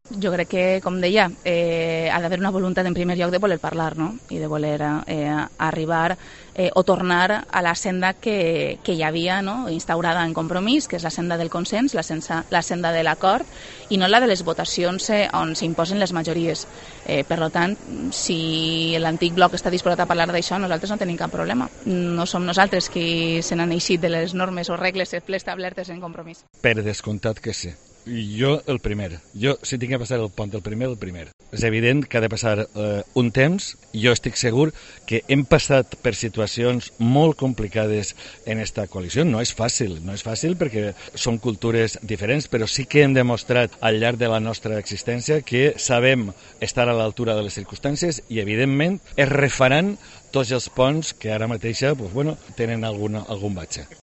Declaraciones Aitana Màs y Joan Baldoví tras la elección de los senadores territoriales